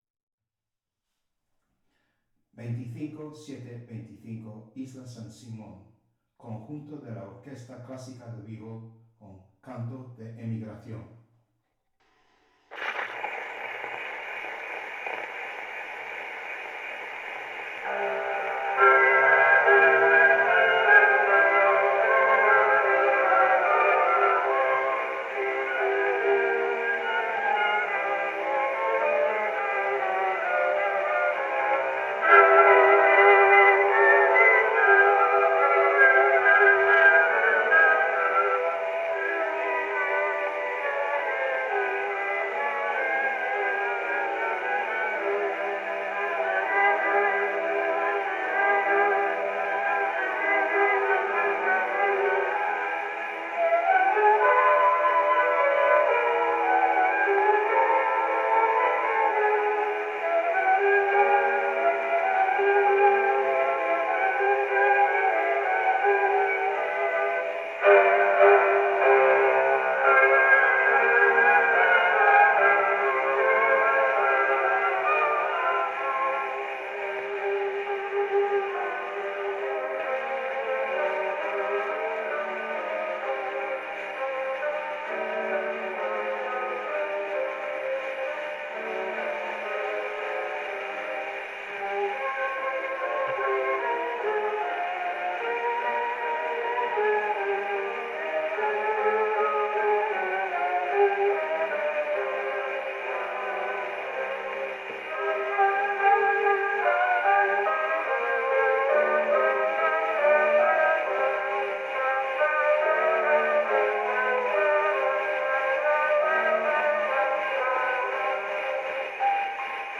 Original sound from the phonographic cylinder.
Recording place: Espazo cafetería